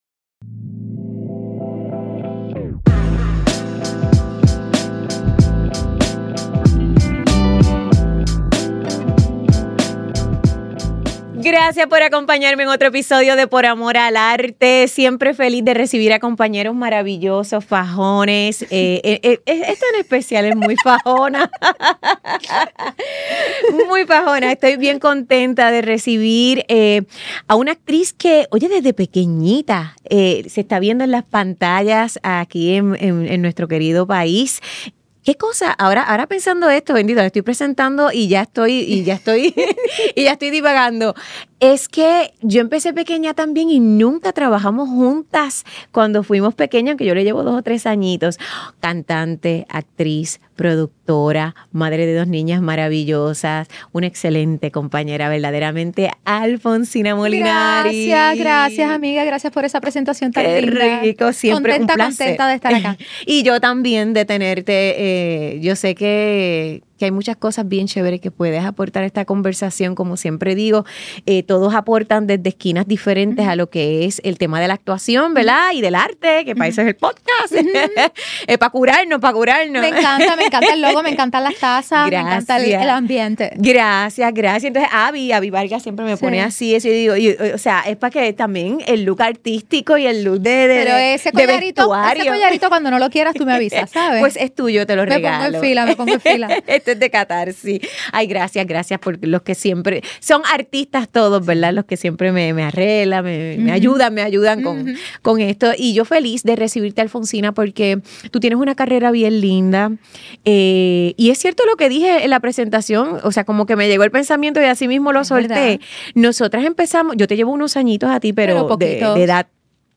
Grabado en GW-Cinco Studio para GW5 Network